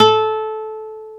NYLON A 3B.wav